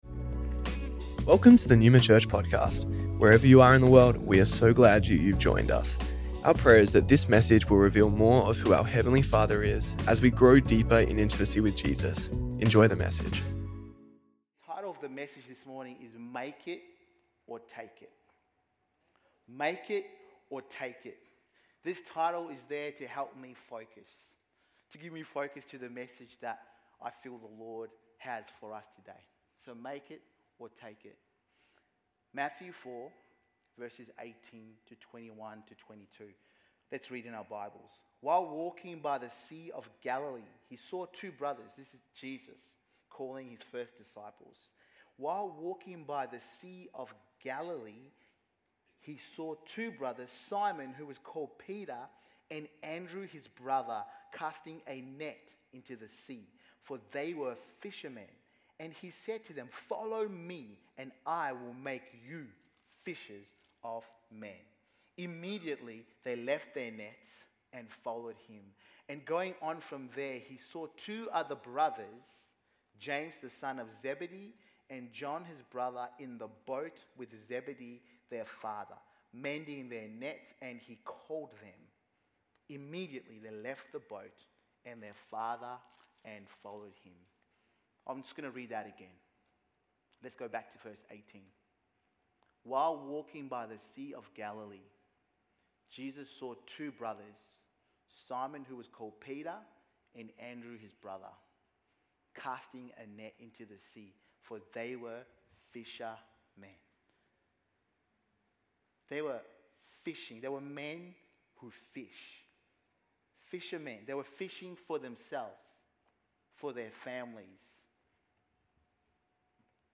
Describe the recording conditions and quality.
Neuma Church Melbourne South Originally Recorded at the 10AM service on Sunday 23rd February 2025